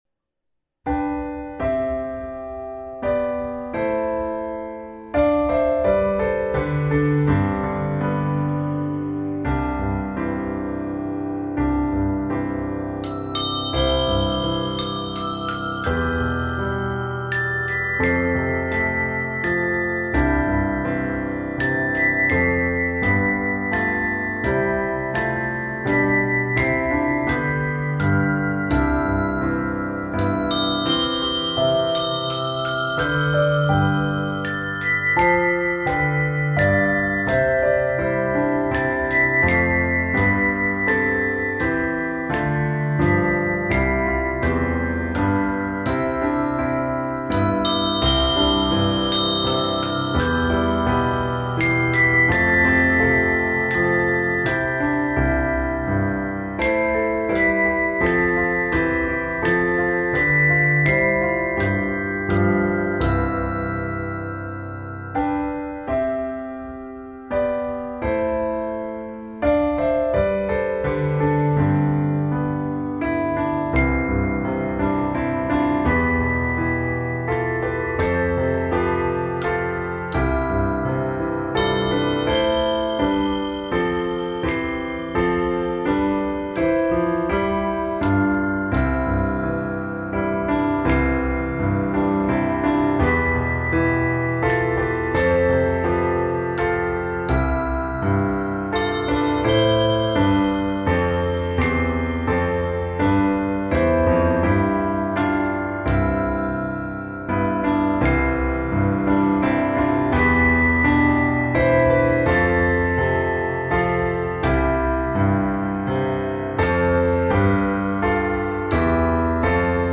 bell tree setting
Christmas carol
Less than a full handbell choir: Solo Ringer